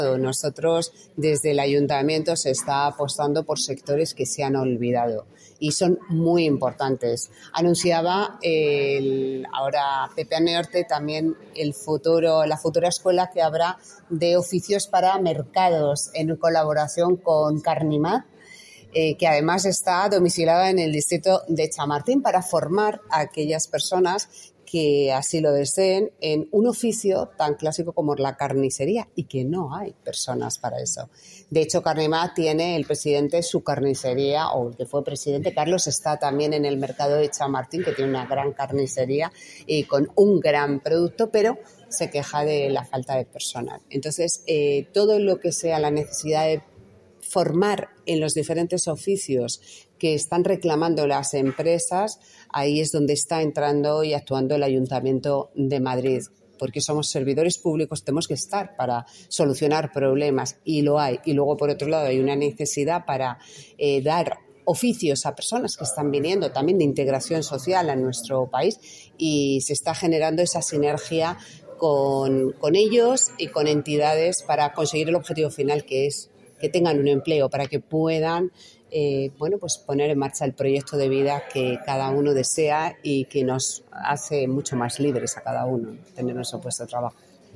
Nueva ventana:Declaración de Yolanda Estrada
Declaración de la concejala de Chamartín, Yolanda Estrada. Chamartín Emplea.mp3